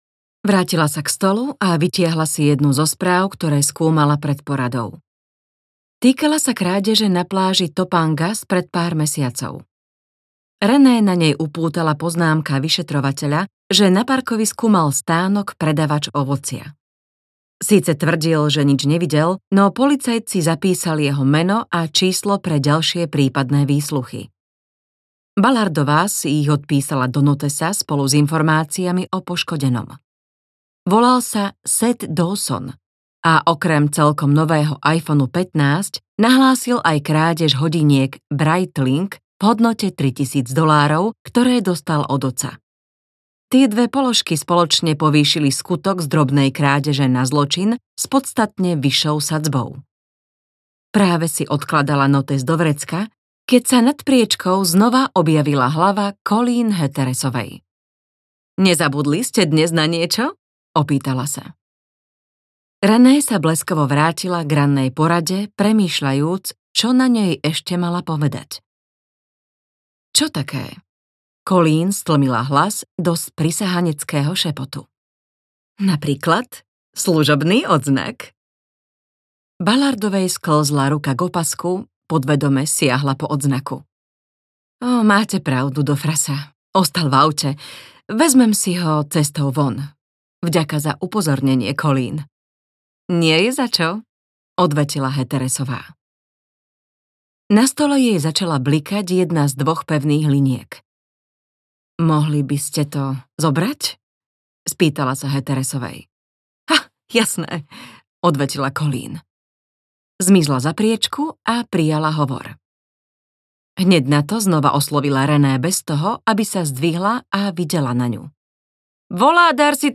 Čakanie audiokniha
Ukázka z knihy